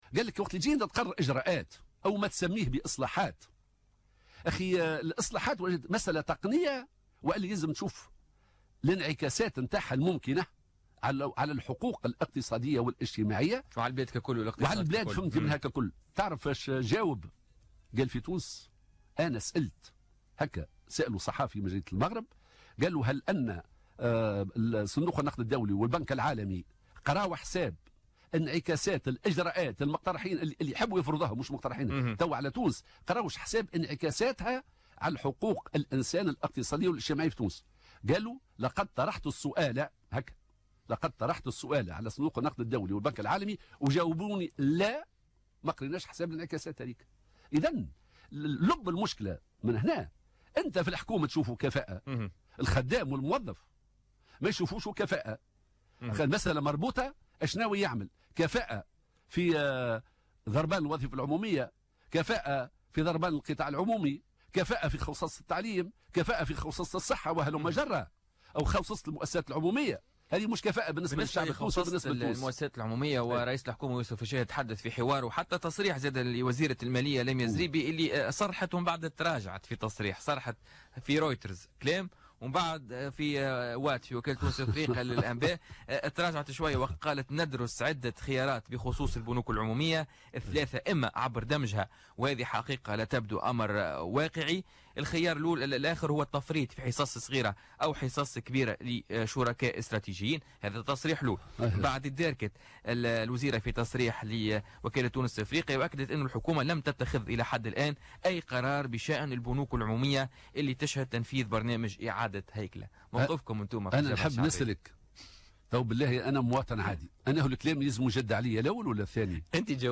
أكد الناطق الرسمي بإسم الجبهة الشعبية حمة الحمامي ضيف بوليتيكا اليوم الخميس 2 مارس 2017 أن الفساد وراء العجز الذي بلغته البنوك العمومية في تونس والتي تسعى الدولة اليوم الى خوصصتها .